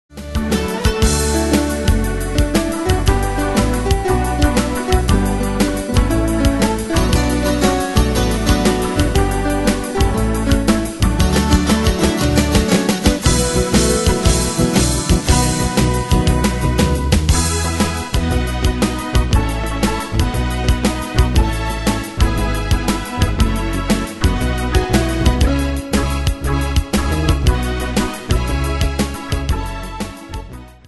Style: PopFranco Ane/Year: 1989 Tempo: 119 Durée/Time: 3.05
Danse/Dance: PopRock Cat Id.
Pro Backing Tracks